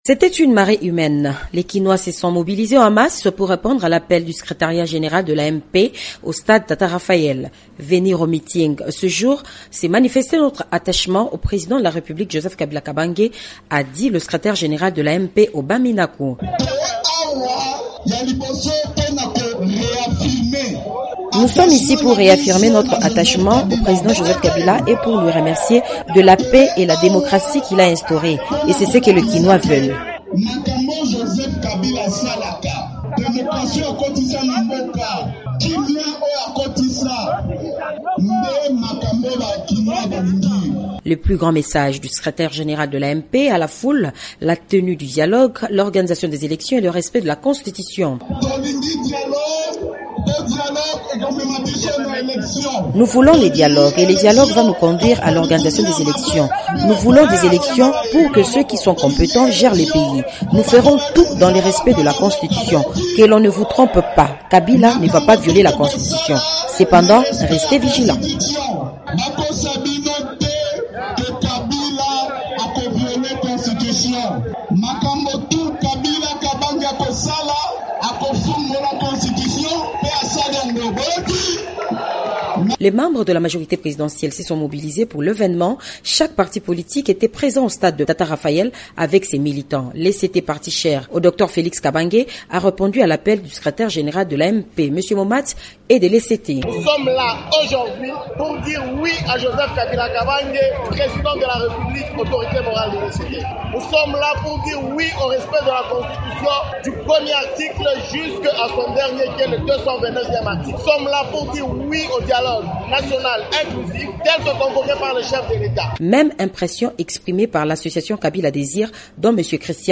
Meeting de soutien à Joseph Kabila à Kinshasa-Reportage de TopCongo FM